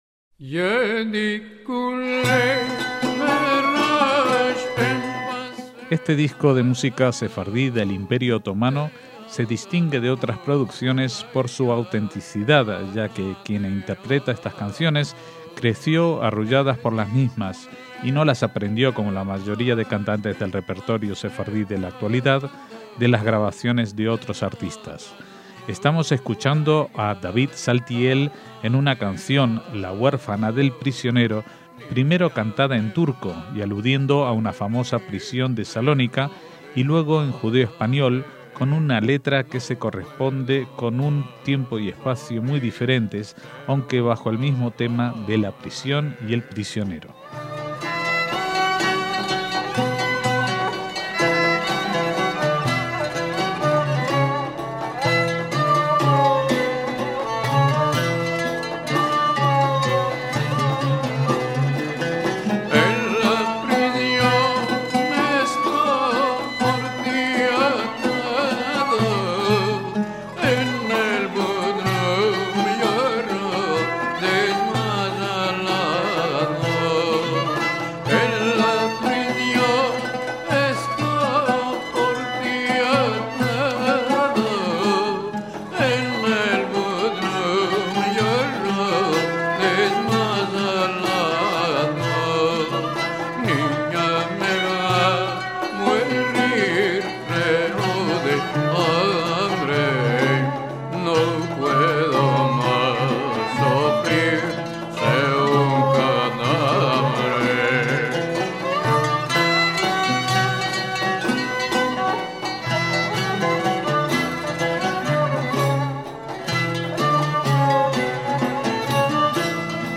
MÚSICA SEFARDÍ
lyra, una especie de violín tradicional